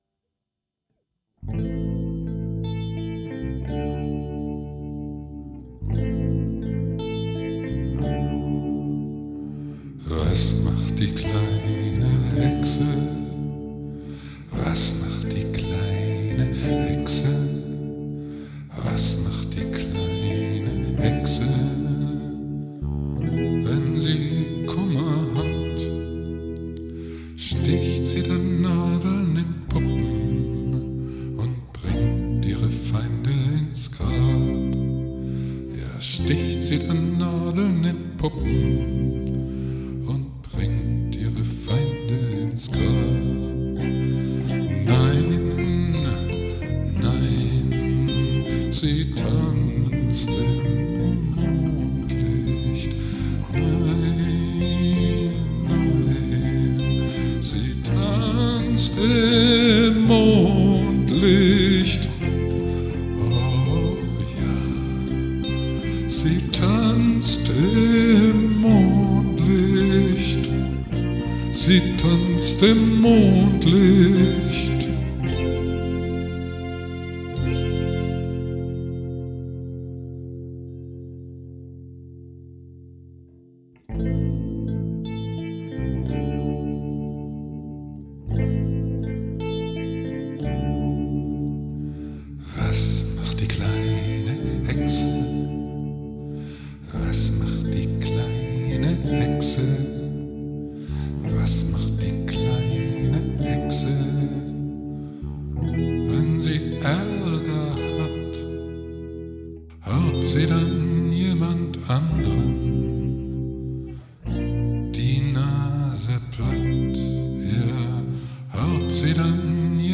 Skizze für „february album writing month“ FAWM2016: E-Gitarre, bundloser Bass, Stimme, Keyboards